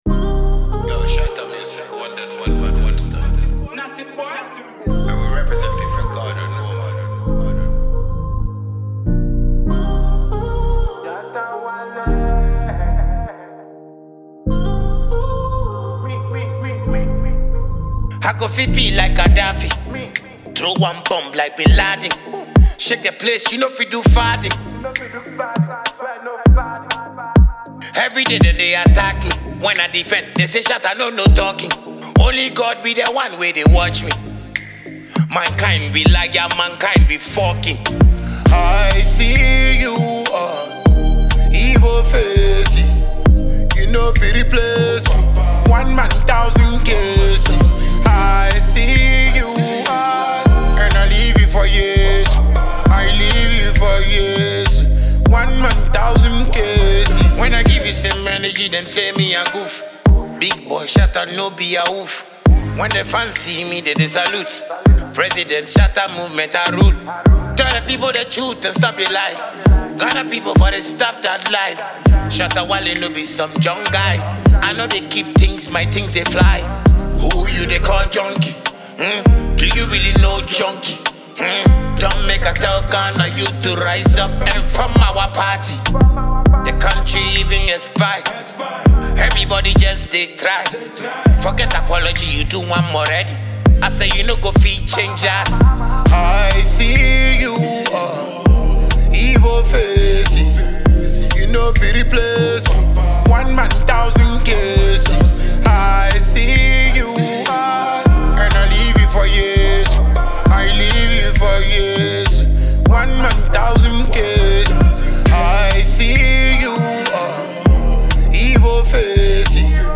a bold and expressive dancehall release
Genre: Dancehall